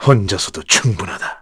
voices / heroes / kr
Crow-Vox_Victory_kr.wav